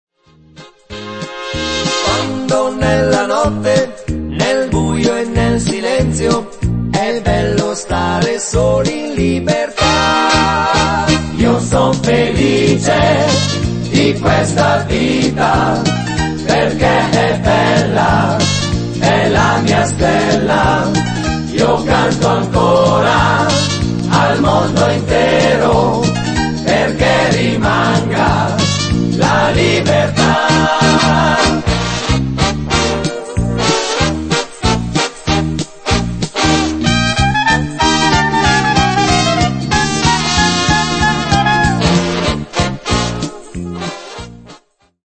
fox